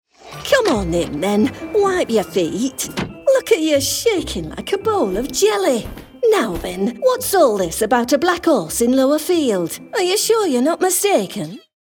Her natural accent is neutral RP but she can also deliver convincing West Country, General Northern and Estuary accents.
standard british | natural
ANIMATION 🎬
farmer/bloke